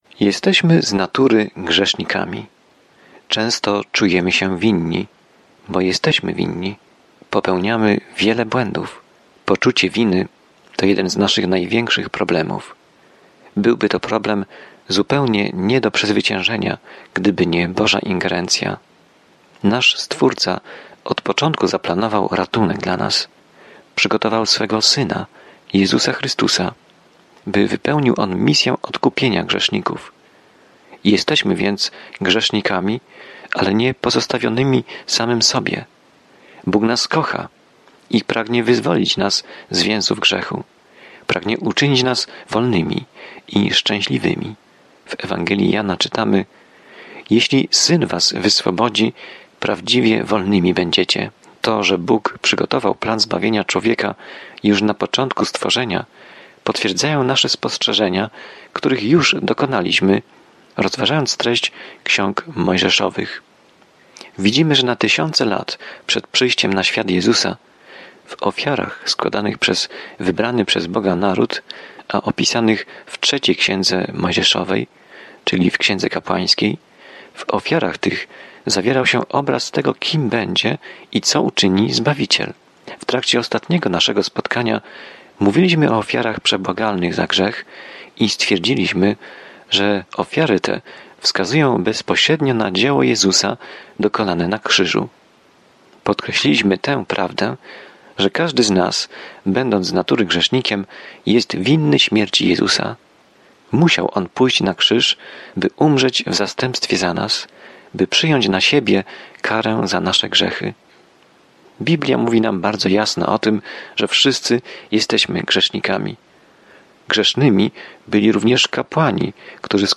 W oddawaniu czci, ofierze i czci Księga Kapłańska odpowiada na to pytanie starożytnego Izraela. Codziennie podróżuj przez Księgę Kapłańską, słuchając studium audio i czytając wybrane wersety słowa Bożego.